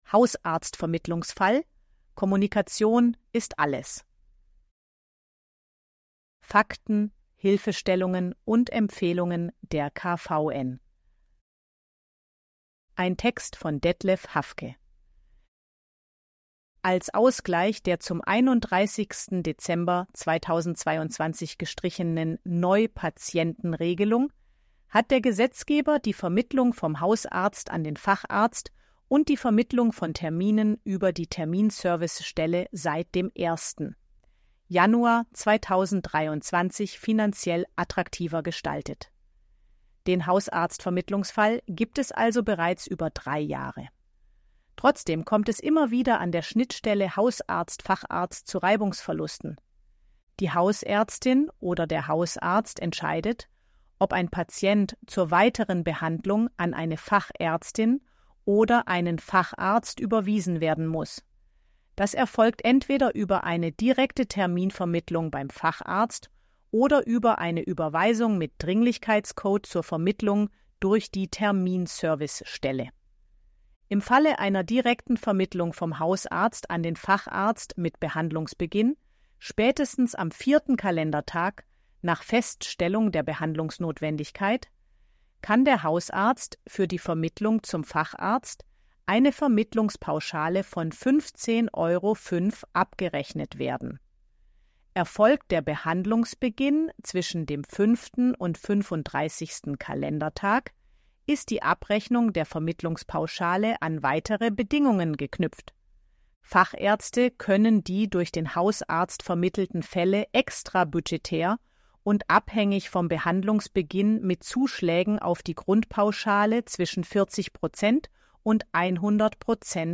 ElevenLabs_KVN261_11_Leonie.mp3